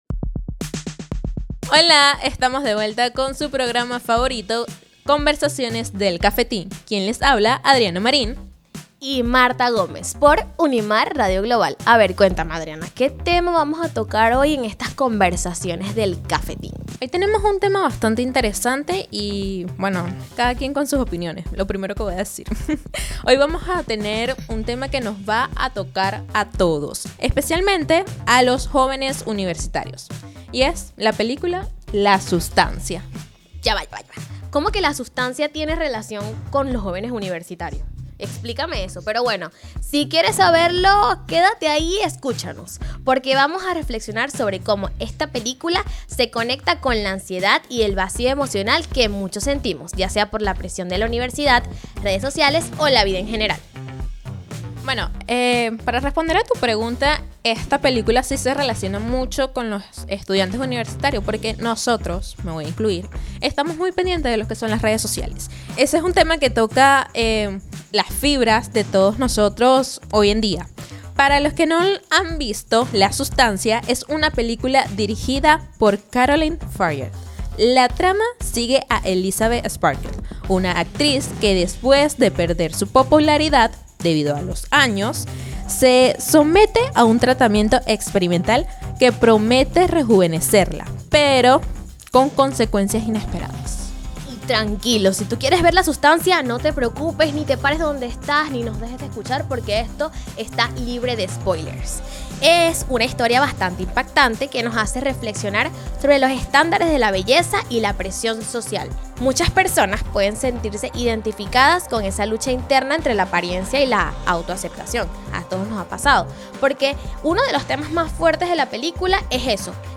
dos estudiantes de Comunicación Social
te invitan a formar parte de un diálogo ágil y sin complejos sobre los temas que dominan el día a día universitario y más allá.